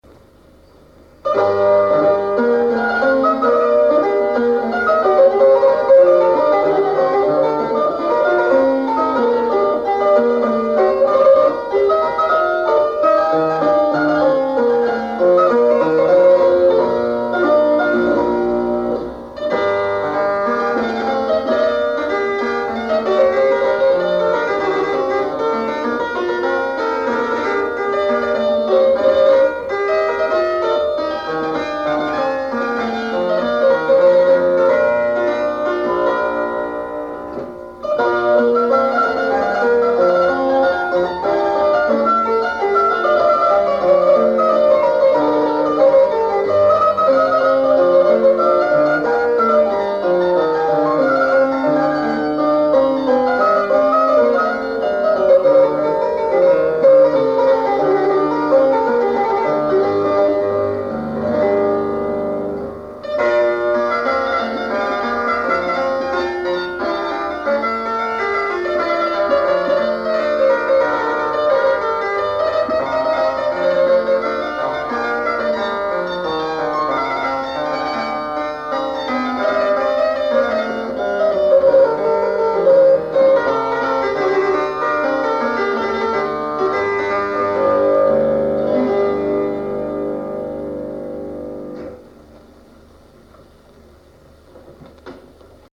Although she never made commercial recordings, she gave many broadcast recitals for the BBC.
Radio announcement from 1966